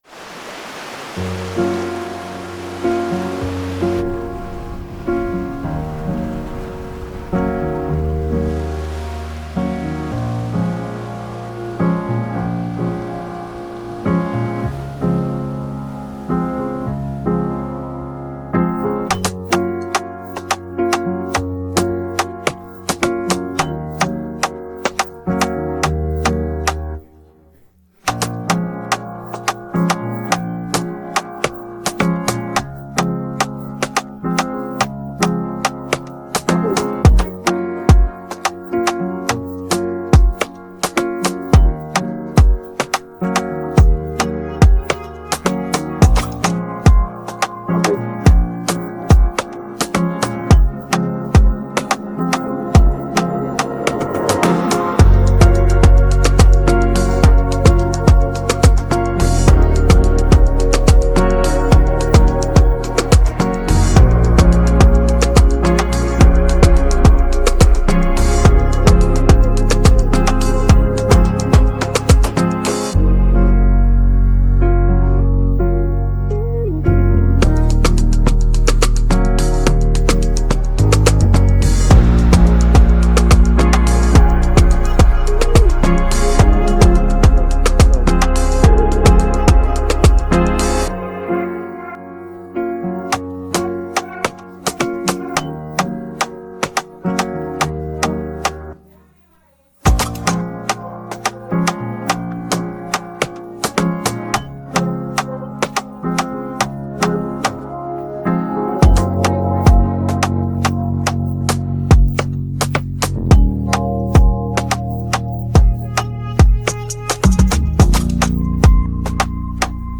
Hip hopPop